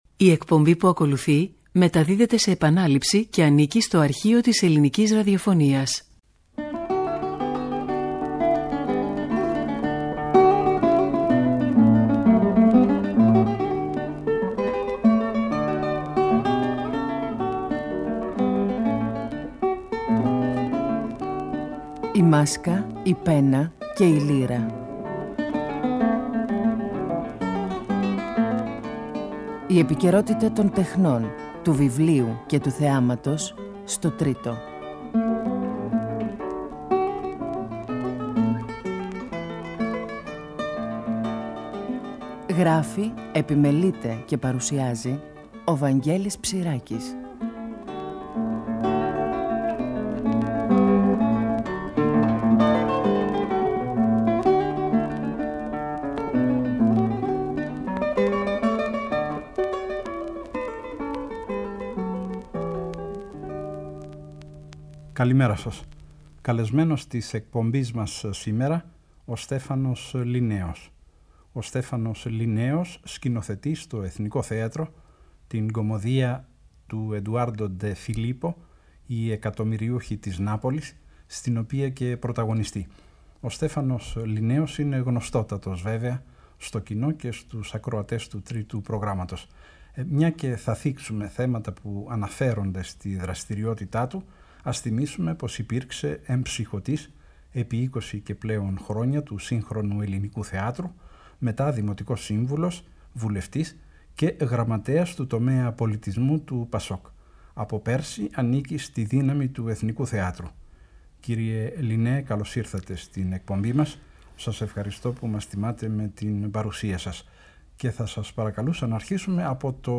ΣΥΝΕΝΤΕΥΞΗ